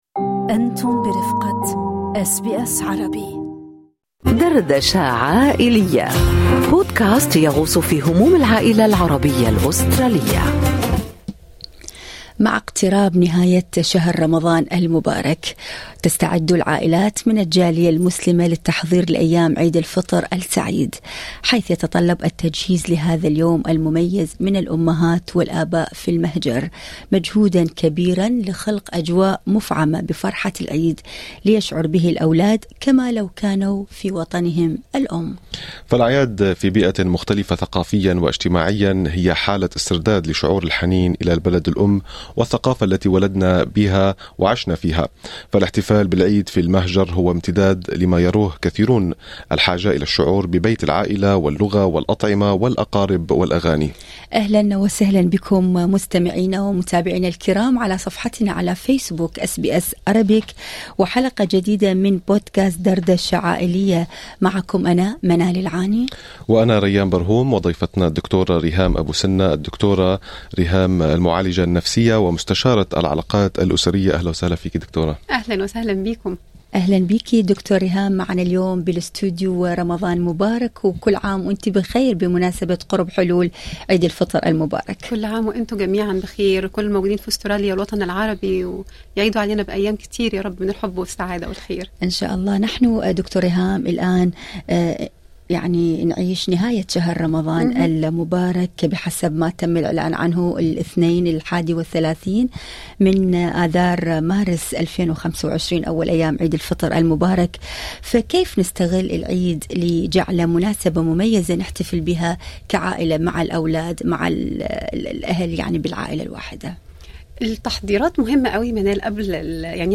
خبيرة أسرة تشرح كيف نجعل العيد فرصة للتسامح ومساعدة المحتاج